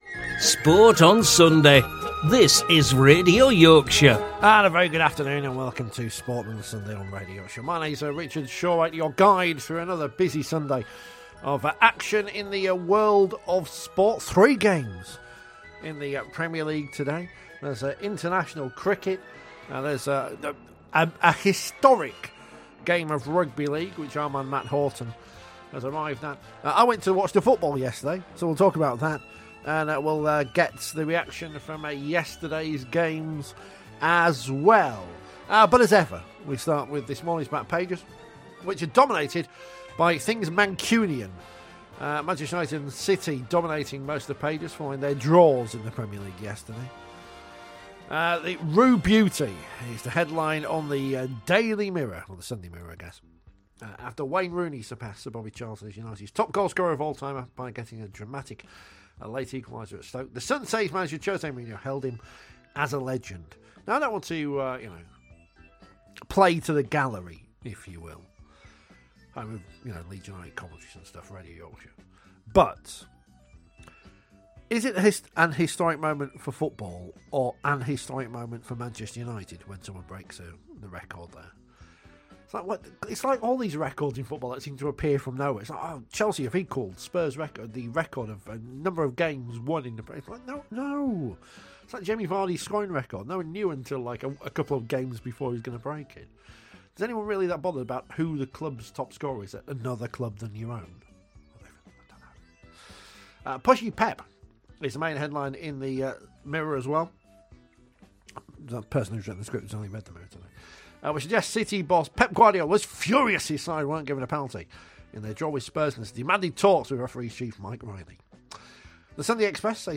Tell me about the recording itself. joins us LIVE from Hull